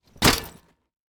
household
Duffle Bag Drop Metal Surface